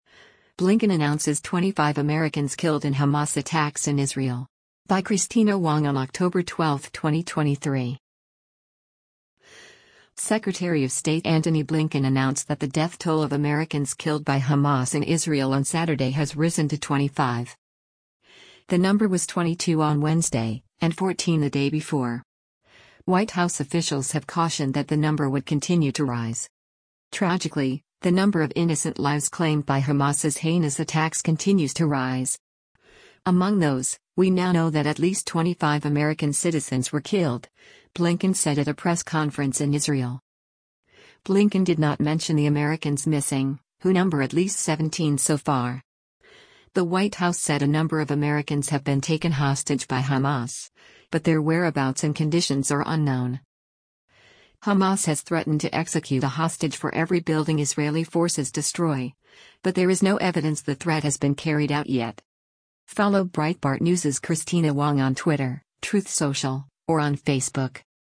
“Tragically, the number of innocent lives claimed by Hamas’s heinous attacks continues to rise. Among those, we now know that at least 25 American citizens were killed,” Blinken said at a press conference in Israel.